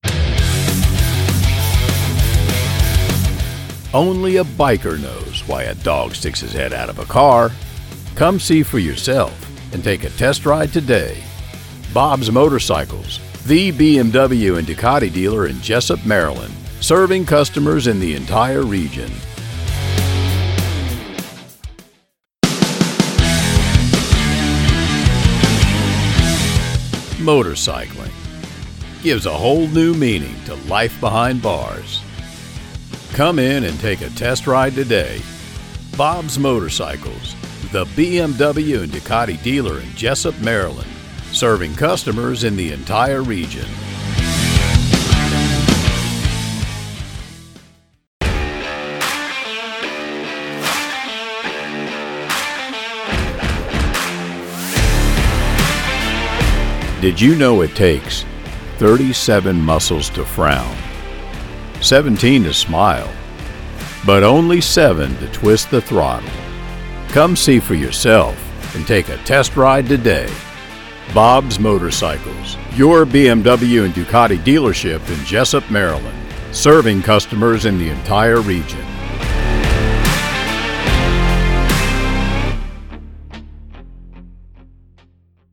Motorcycle Dealership Promos
Narrator, Dangerous, Deep, Evil, Explosive, Forceful, Gravelly, Gritty, Informative, Low Pitched, Manly/Masculine, Movie Trailer, Cynical, Ominous, Reassuring, Rugged, Skeptical, Strong, Trustworthy, Voice Of God, Announcer, Blue Collar, Abrupt, Aggressive, Angry, Attractive, Authoritative, Big, Bold, Booming, Bossy, Breathy, Burly, Charismatic, Clear, Compassionate, Condescending, Confident
Middle Aged
My studio is equipped with a RODE 5th Generation NT Mic, a VOLT 1 Audio Interface using Audacity DAW.